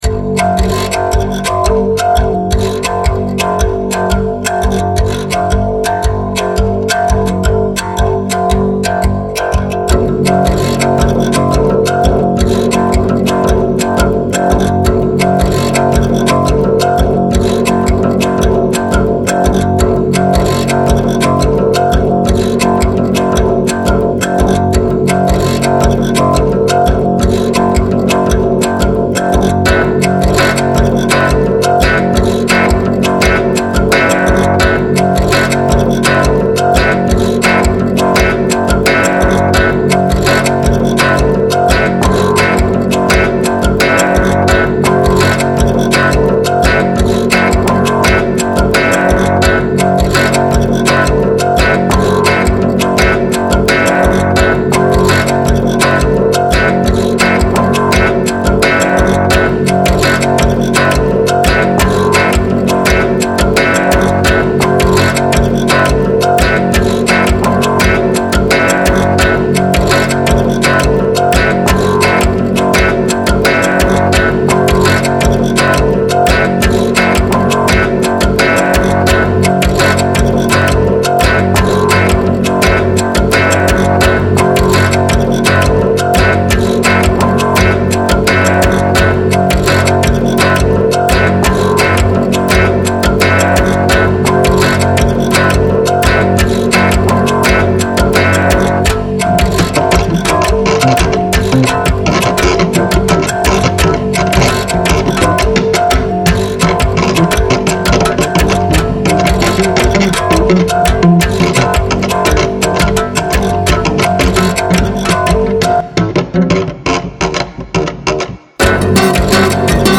CD 1 – PURE ZITHER